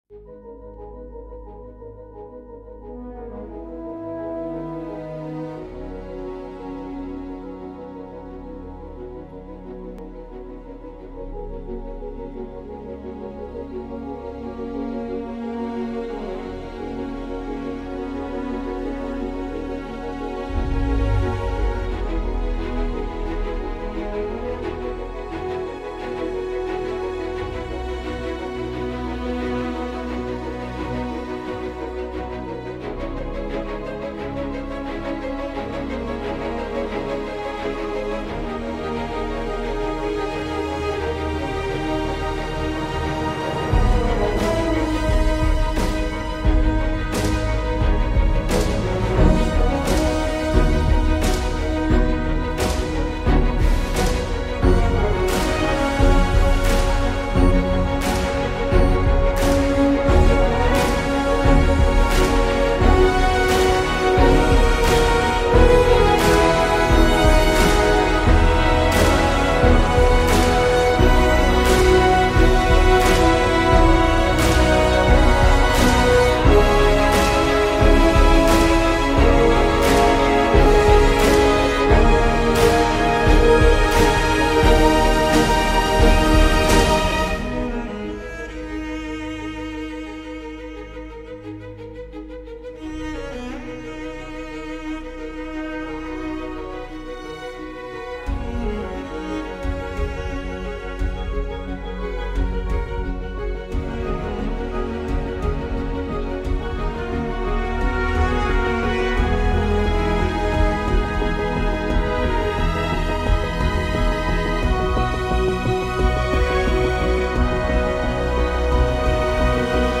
это мощная и вдохновляющая композиция
инструментальная версия